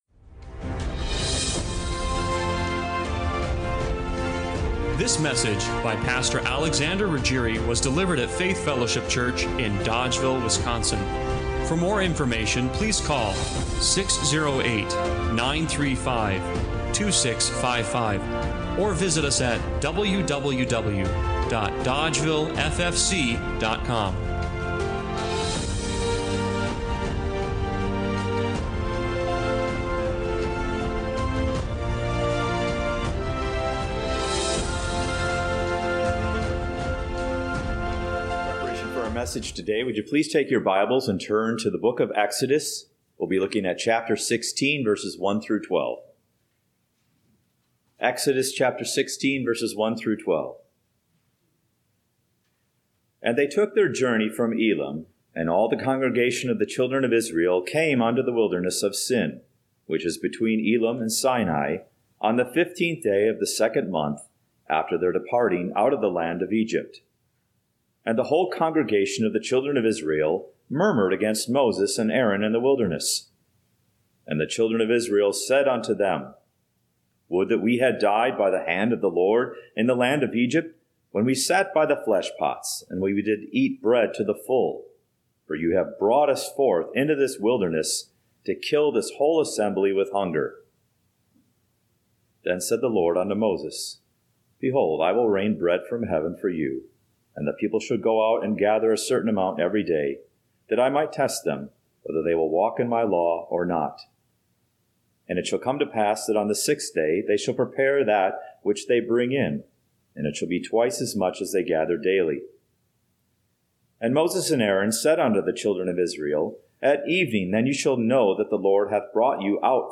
Exodus 16:1-12 Service Type: Sunday Morning Worship Bible Text